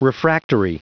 Prononciation du mot refractory en anglais (fichier audio)